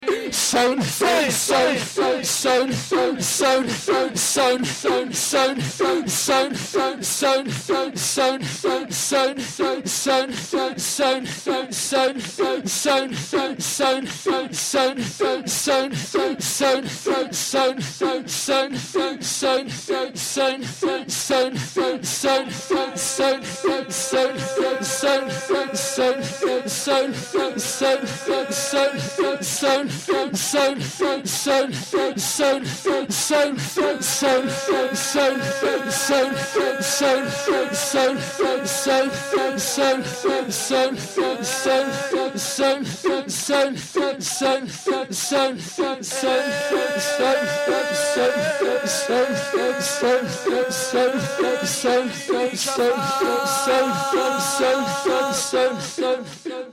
روضه العباس
zekr.mp3